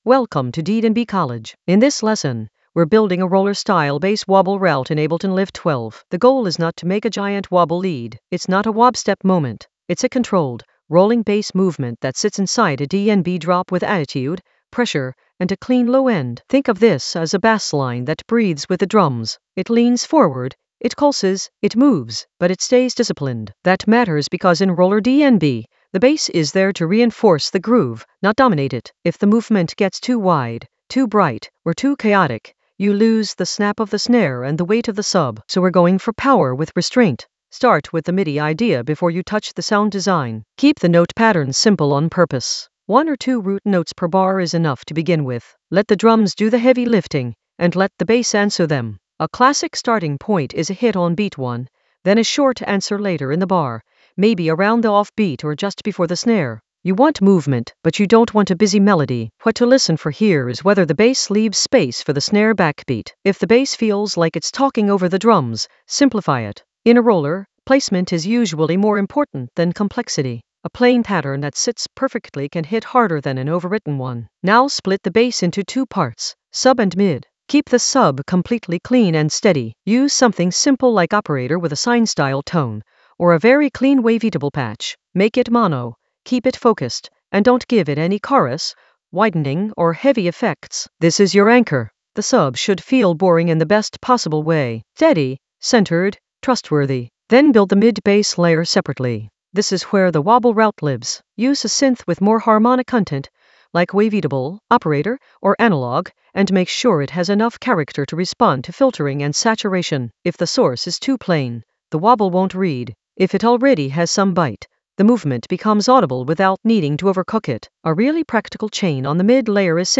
An AI-generated intermediate Ableton lesson focused on Roller Tactics approach: a bass wobble route in Ableton Live 12 in the FX area of drum and bass production.
Narrated lesson audio
The voice track includes the tutorial plus extra teacher commentary.